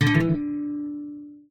guitar_ded.ogg